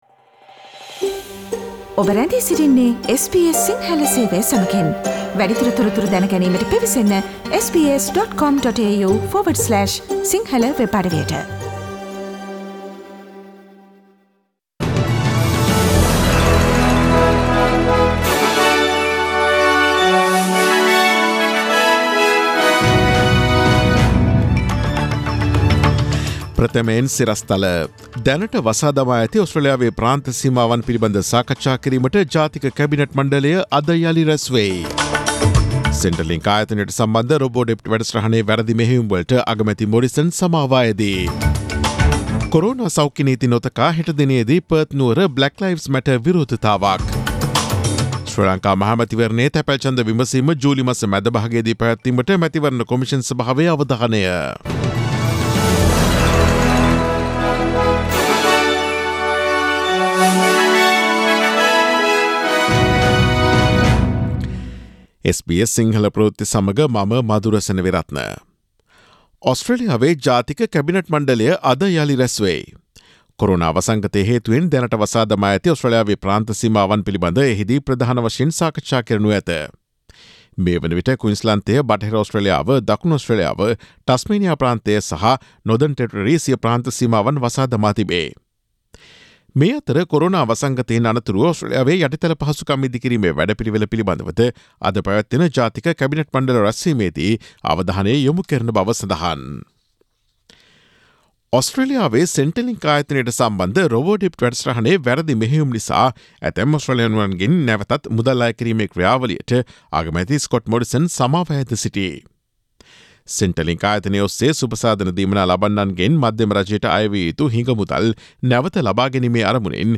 Daily News bulletin of SBS Sinhala Service: Friday 12 June 2020